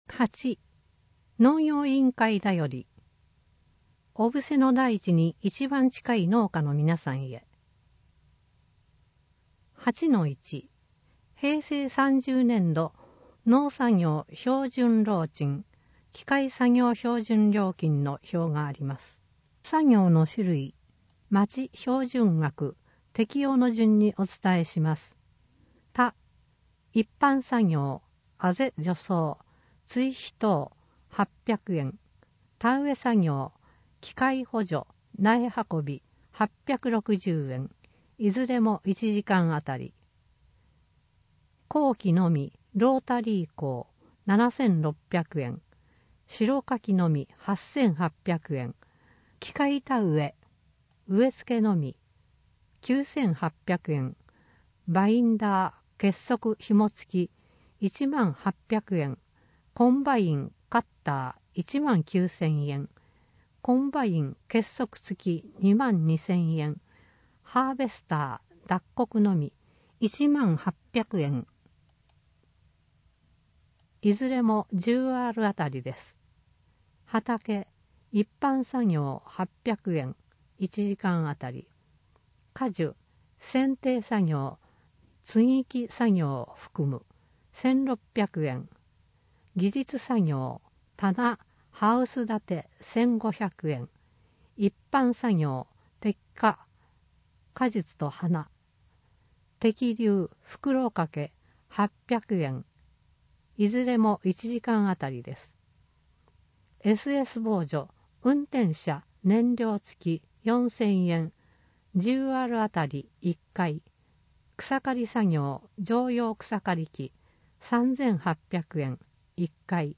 毎月発行している小布施町の広報紙「町報おぶせ」の記事を、音声でお伝えする（音訳）サービスを行っています。 音訳は、ボランティアグループ そよ風の会の皆さんです。